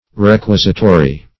Search Result for " requisitory" : The Collaborative International Dictionary of English v.0.48: Requisitory \Re*quis"i*to*ry\ (-t?-r?), a. Sought for; demanded.